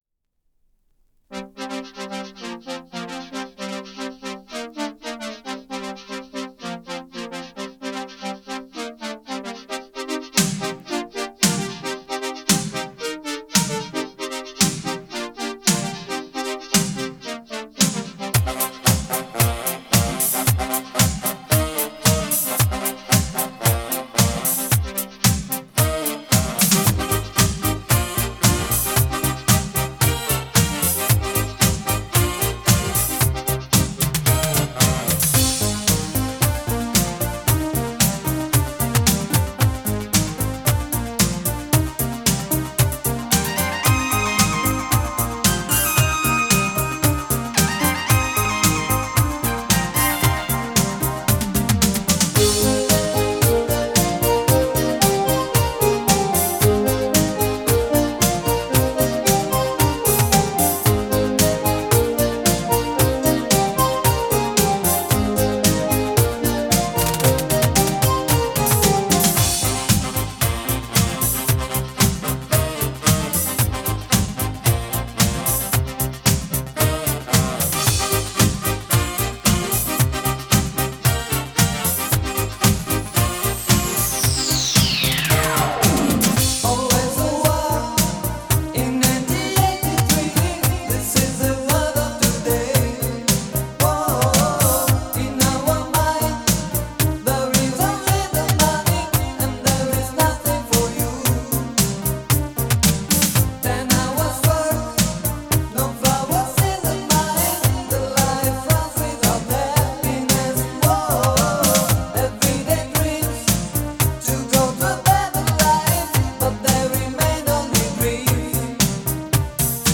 流行、意大利迪斯科
立体声